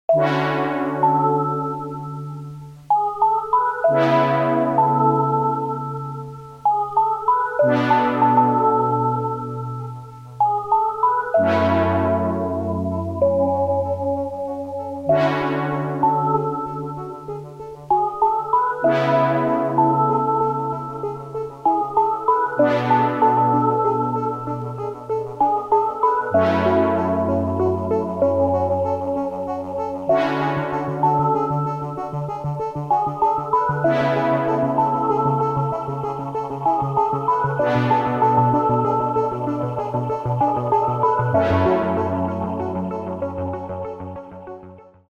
без слов
нарастающие , electronic